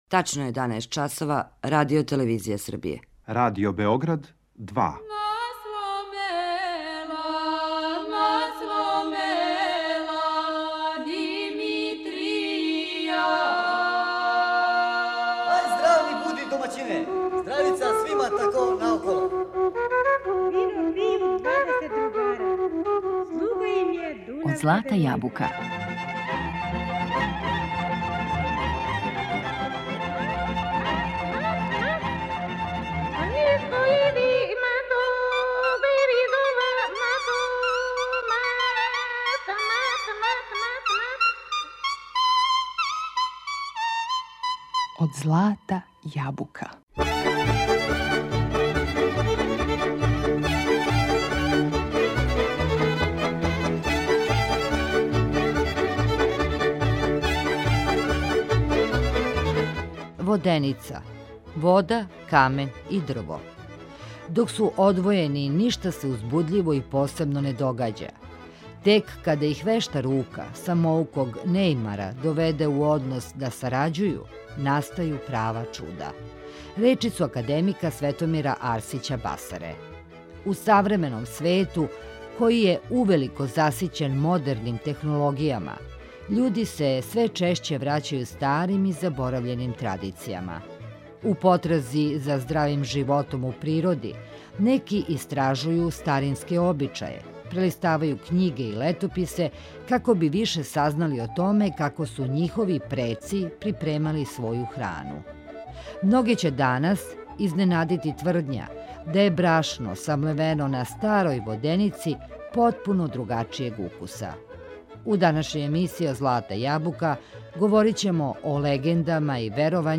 У данашњој емисији Од злата јабука говоримо о легендама и веровањима којима је инспирација била воденица, уз одабрану изворну музику.